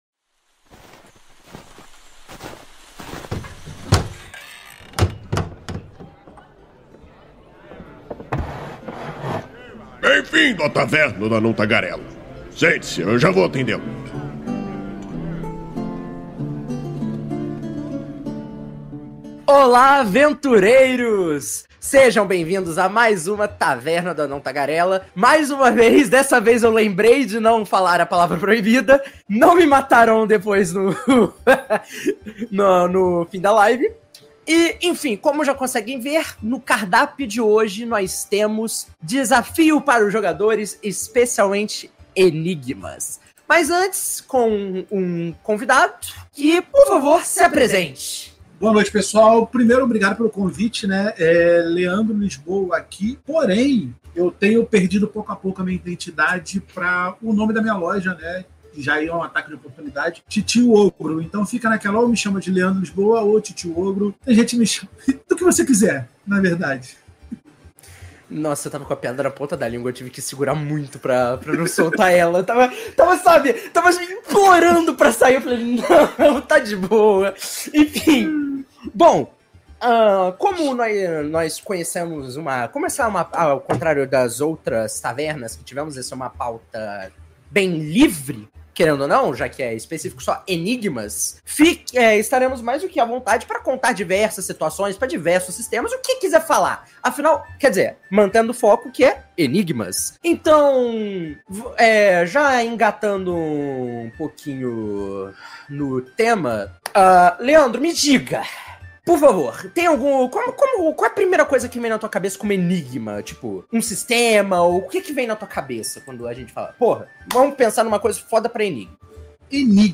Venha entender como esse artifício é importante para seu RPG, como torná-lo mais interessante para os jogadores e saiba dicas de como compor enigmas na sua sessão. A Taverna do Anão Tagarela é uma iniciativa do site Movimento RPG, que vai ao ar ao vivo na Twitch toda a segunda-feira e posteriormente é convertida em Podcast.